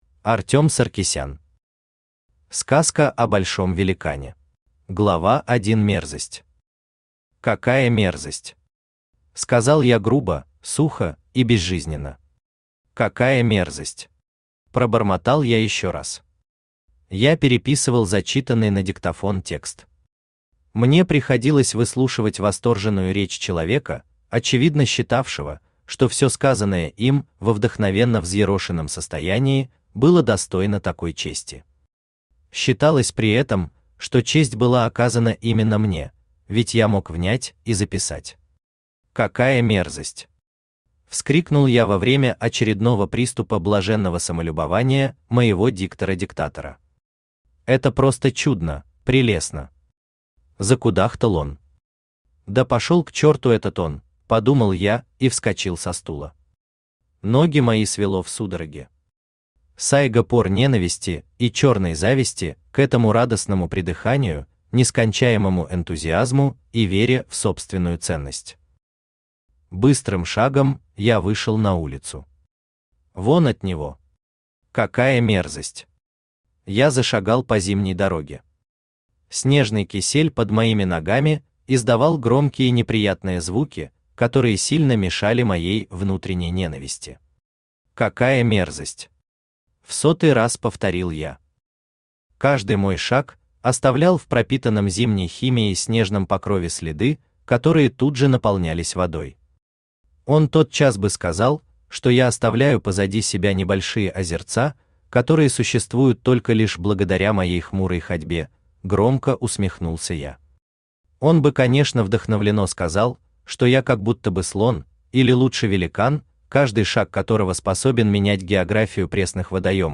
Аудиокнига Сказка о большом великане | Библиотека аудиокниг
Aудиокнига Сказка о большом великане Автор Артём Артурович Саркисян Читает аудиокнигу Авточтец ЛитРес.